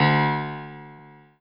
piano-ff-18.wav